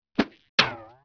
1 channel
archer_volley2.wav